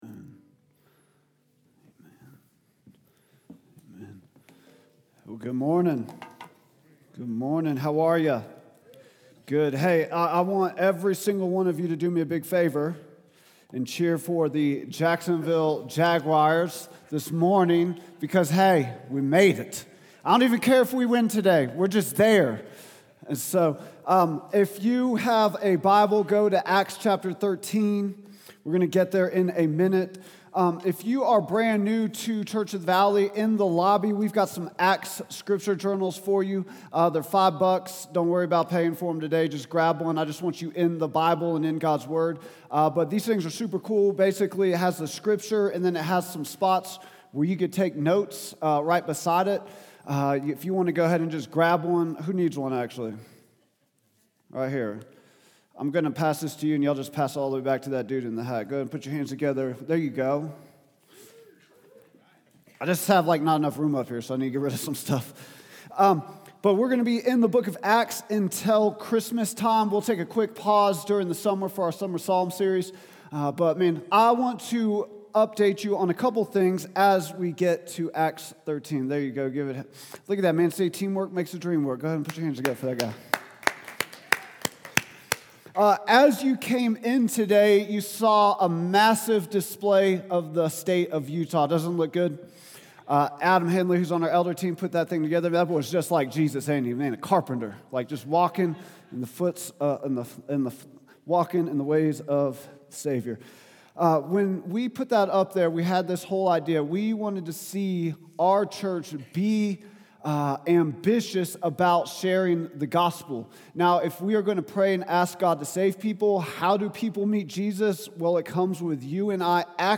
preaches on Acts 13:1-12.